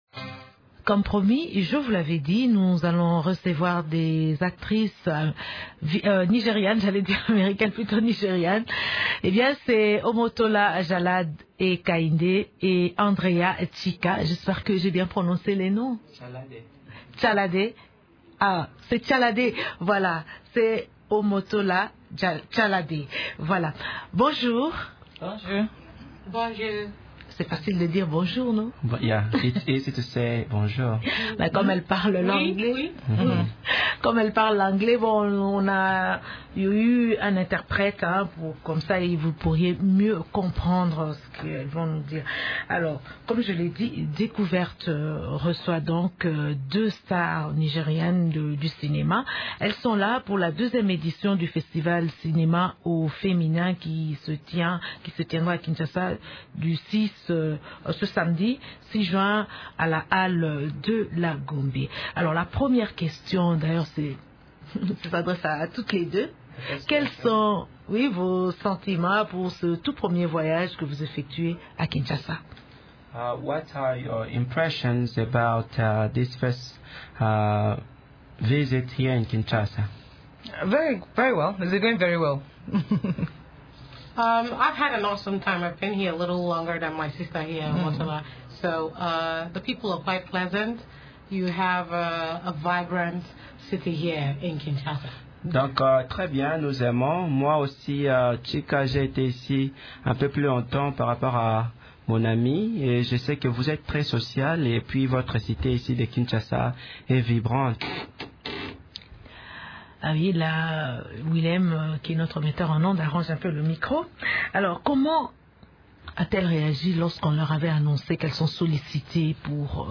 Elle a lancé cet appel, vendredi 5 juin, au cours de l’émission «Découverte» de Radio Okapi. Pour réussir dans ce métier, Omotola estime que les femmes ont intérêt à doubler leurs efforts.